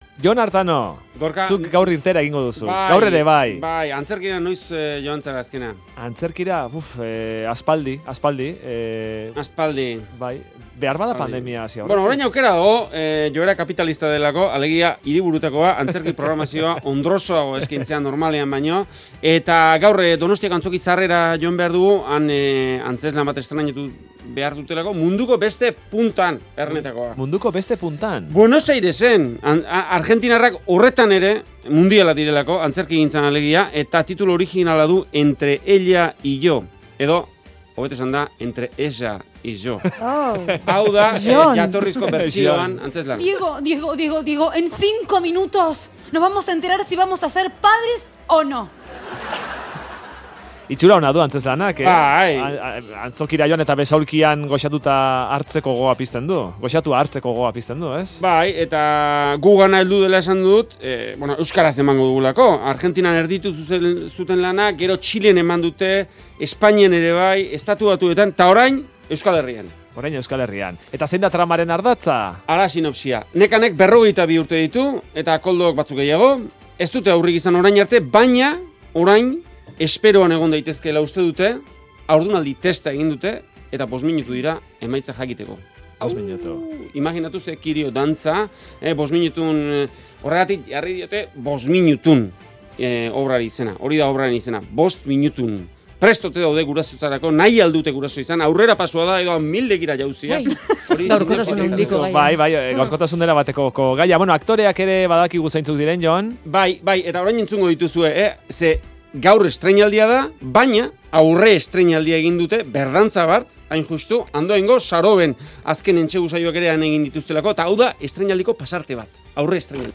Uda gau bateko estreinaldia(beno... estreinaldiaren aurretxoko kontuak, Donostiako Anzoki Zaharretik)